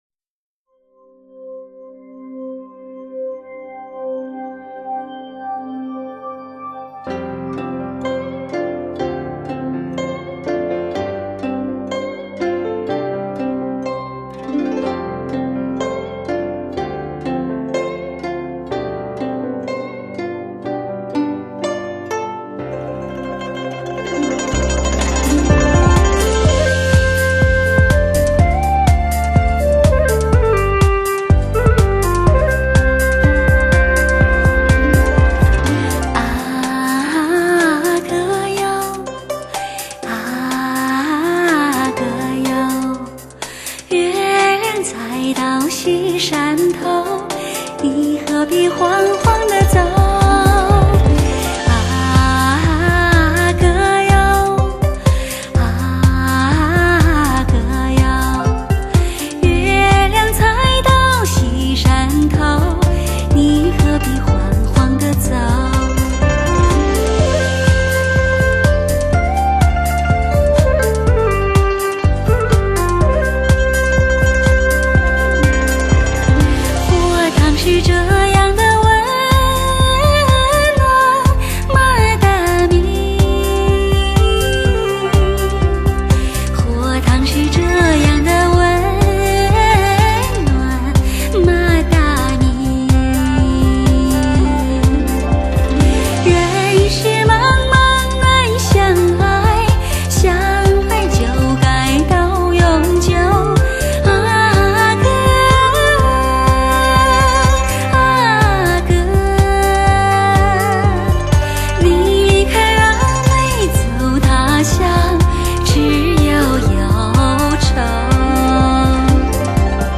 搭配，大提琴，二胡，古筝，巴乌，笛子等充满民族气息的乐器，加之歌手时而轻柔
甜美、婉转流畅，时而纯真炽热却细腻动人的完美把握，亲临其境般，醉在其间。